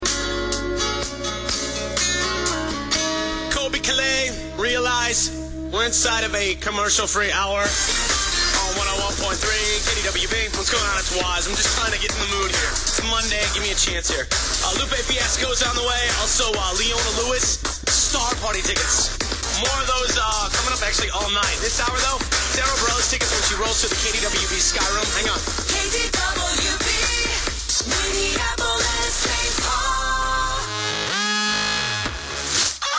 It’s a jingle he has never heard on the air yet.